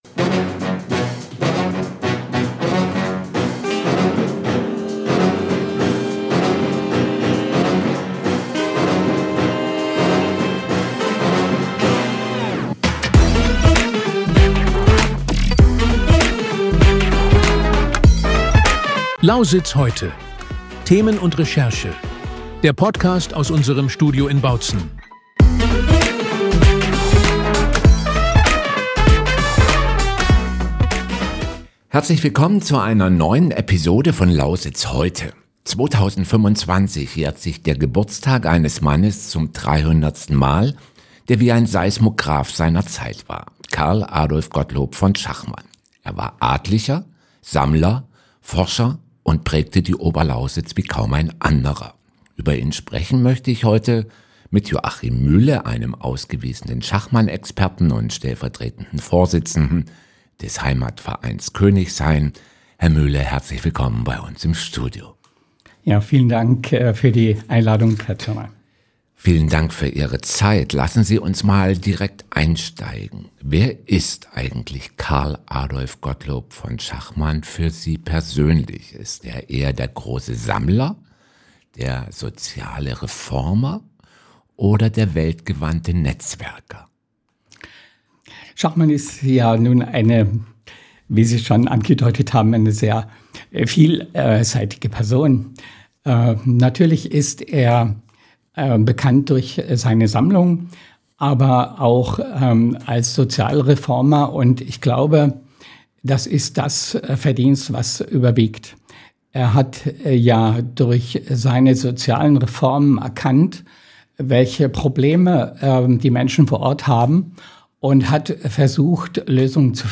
Zum 300. Geburtstag des vielseitigen Adligen taucht dieser Podcast tief in sein Leben ein. Im Gespräch